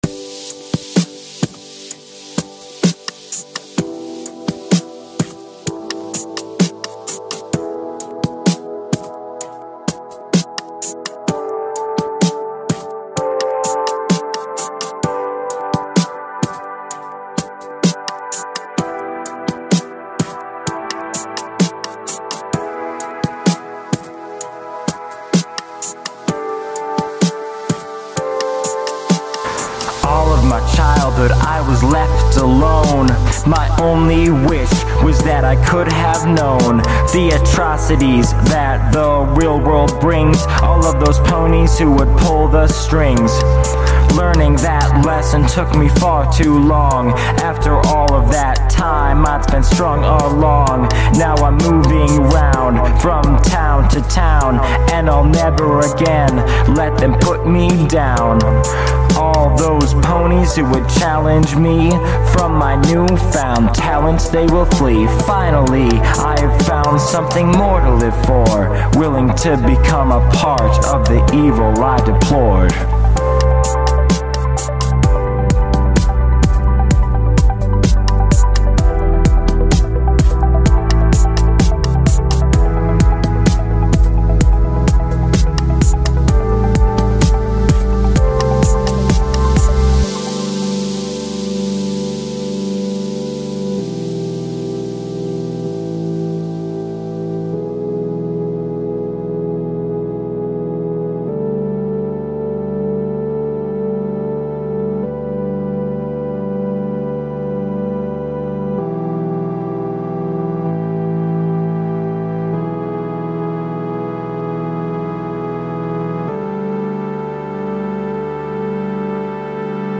weird prog-house/rap thing